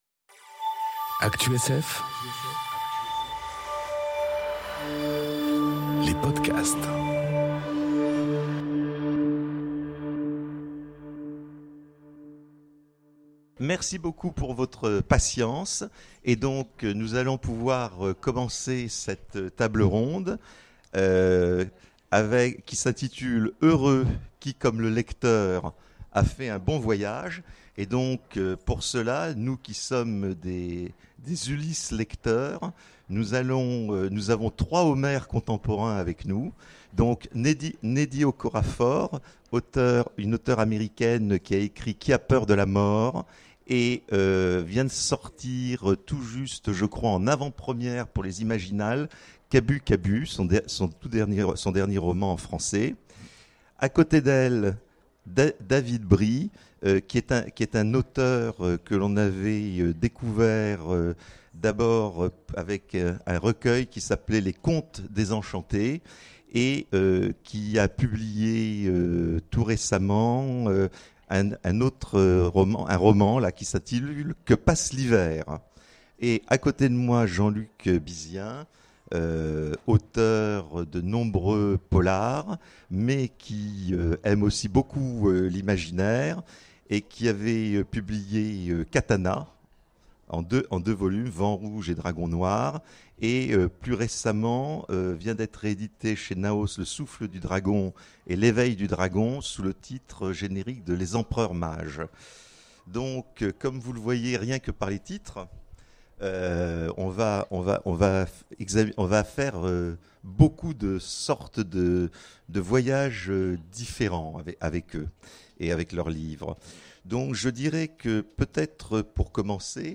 Conférence Heureux qui comme le lecteur... a fait un beau voyage enregistrée aux Imaginales 2018